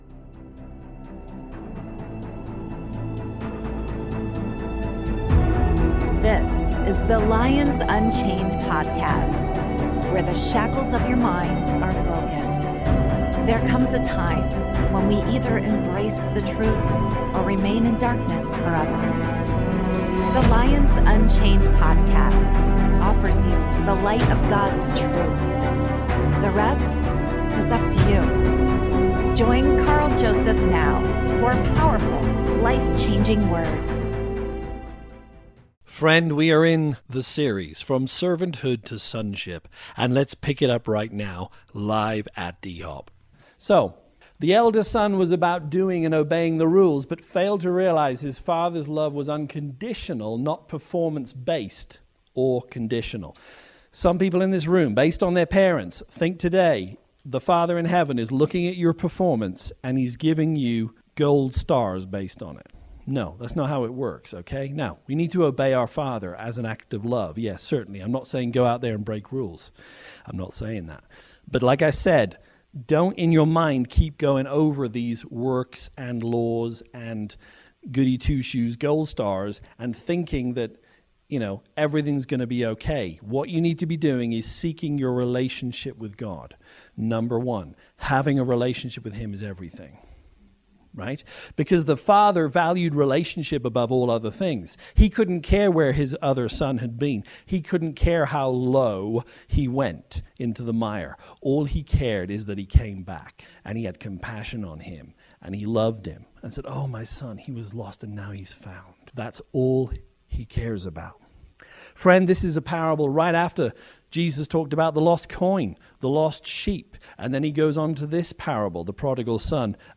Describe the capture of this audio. From Servanthood to Sonship: Part 2 (LIVE)